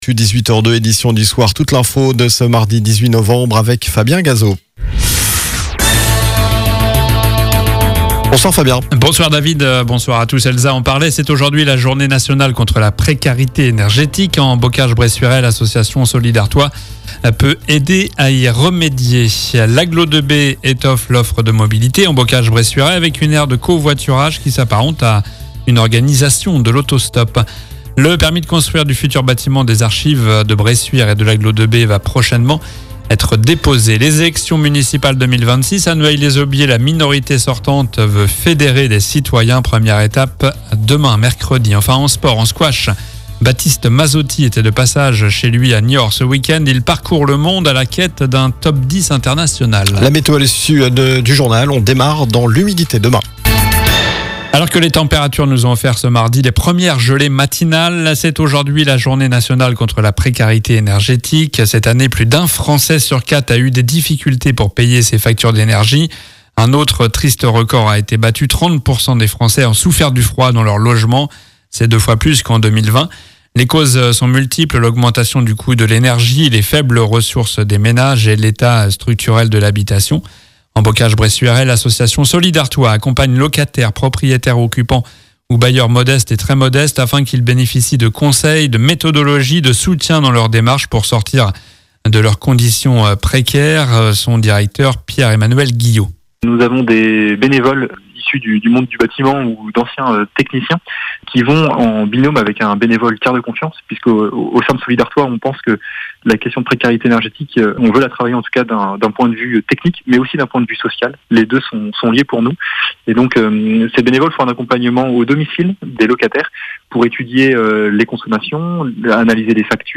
Journal du mardi 18 novembre (soir)